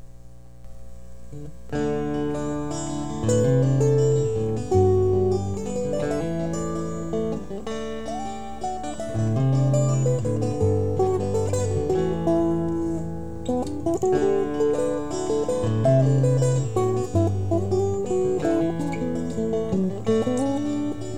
This little amp sounds very sweet.
It won't distort without a pedal or box in front, but has its own unique compression and excellent reaction to pick attack.
Recorded from Tele to Dickerson to computer headset mike!